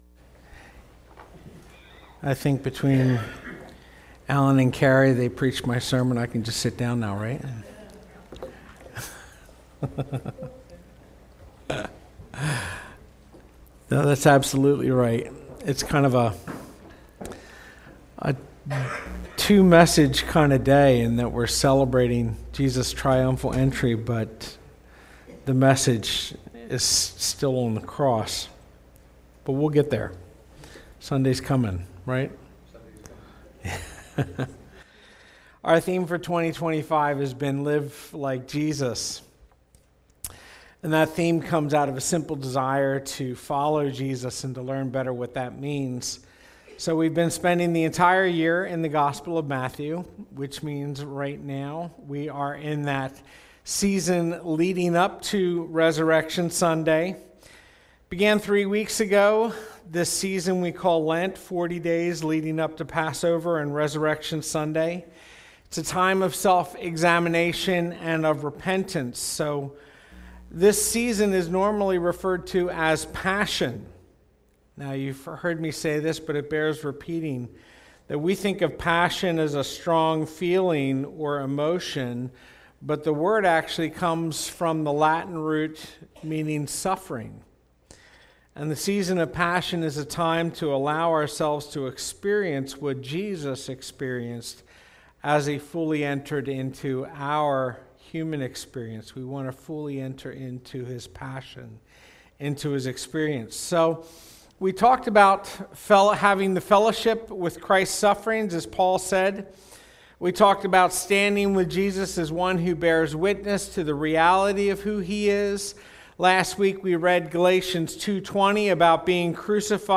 Sermons | Spring City Fellowship